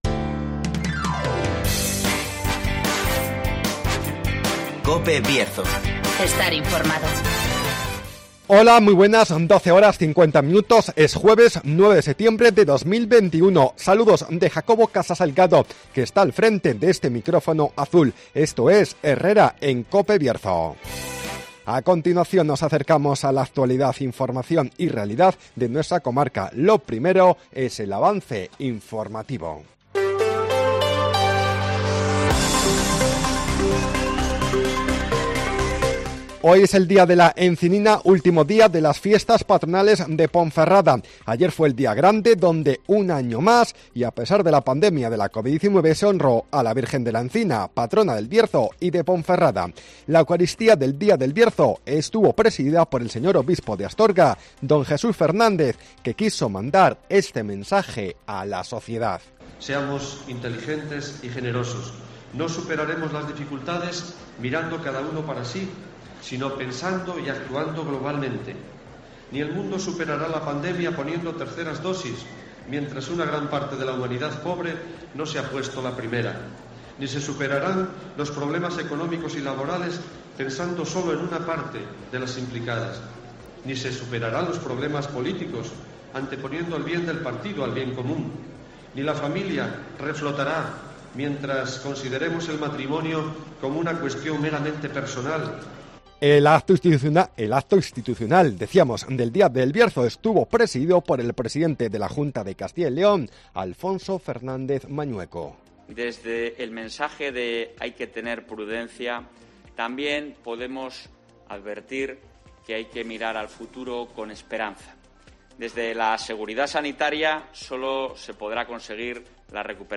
Avance informativo, El Tiempo, Agenda y apuntes del deporte